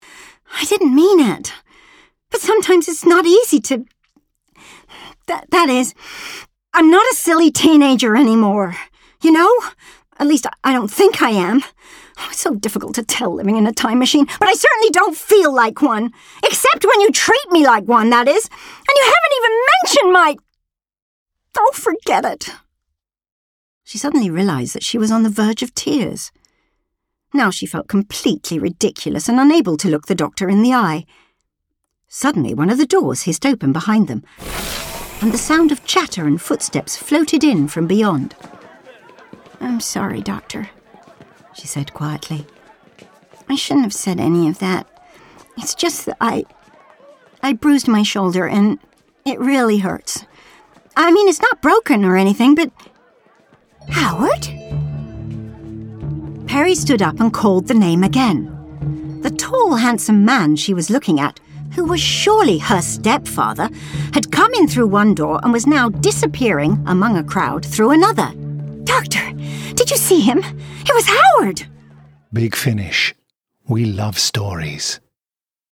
Part of the fun is that Nicola’s real voice is very easy on the ears, and Short Trips lets her use it for narration. She switches between it and Peri and her fabulous Colin imitation and guest characters so effortlessly that I was getting voicework whiplash for the first few minutes until I got used to it.
trailer.